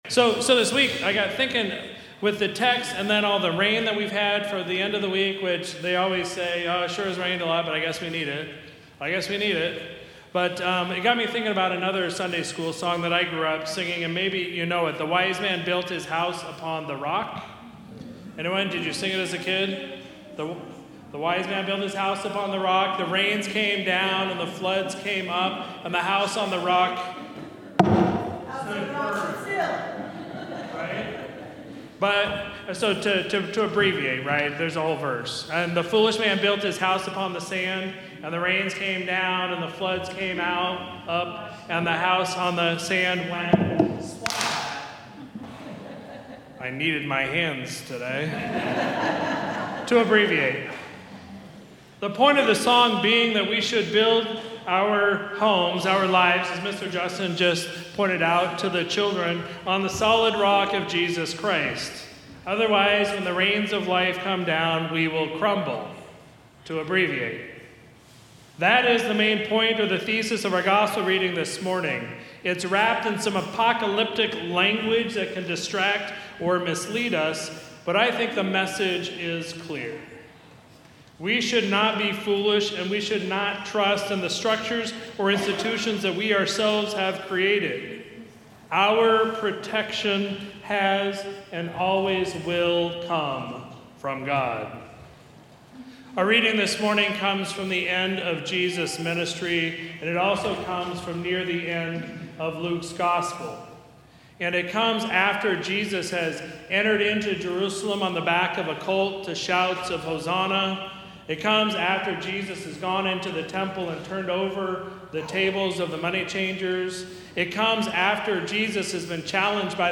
Sermon from Sunday, November 16, 2025